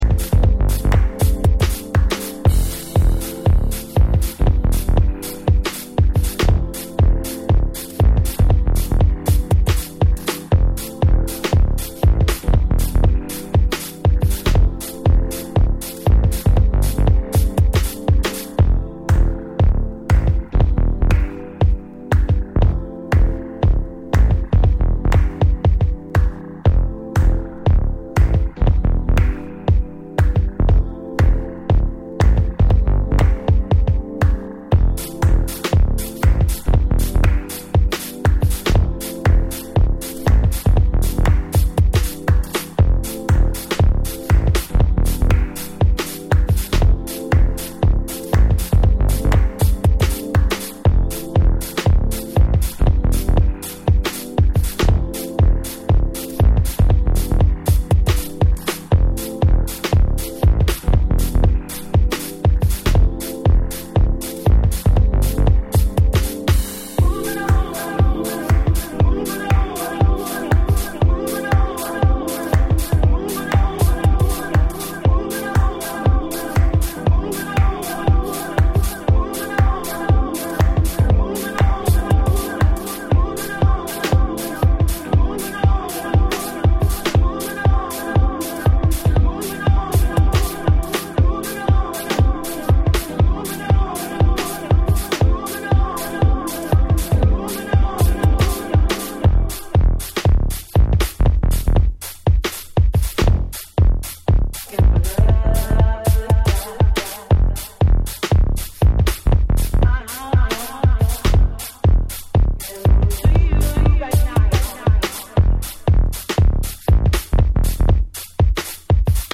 steady 4/4 tracks